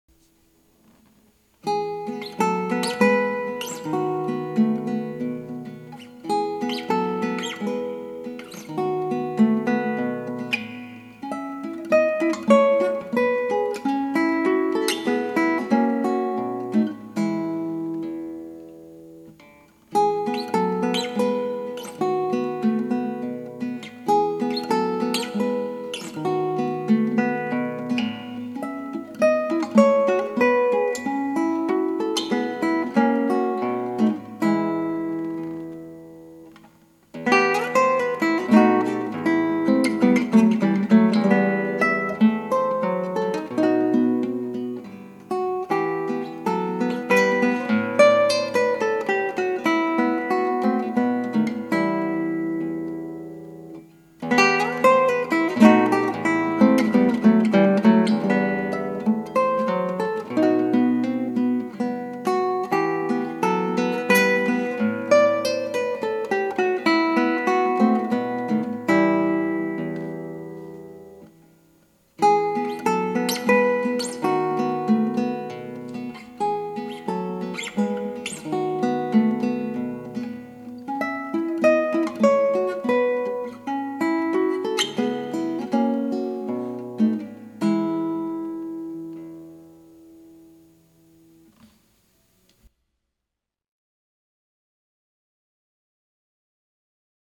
クラシックギター 「やさしい曲もね」 - 「ラグリマ」Lagrima
ギターの自演をストリーミングで提供